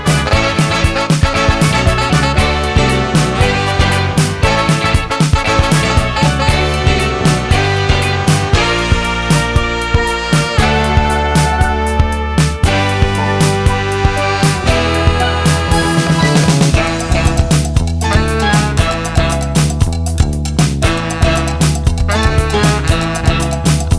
No Backing Vocals Ska 2:51 Buy £1.50